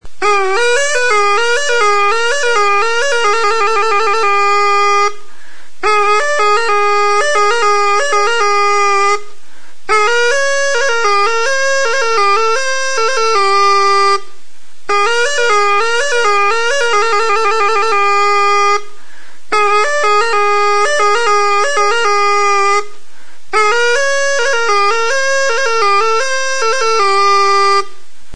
TURUTA; OLO-GARI ZUZTARRA | Soinuenea Herri Musikaren Txokoa
Gari-olo zuztarraren ordez, edateko plastikozko lastotxoarekin ("pajita") egindako turuta. Tonu aldaketarako 2 zulo ditu.
Classification: Aérophones -> Anches -> Double (hautbois)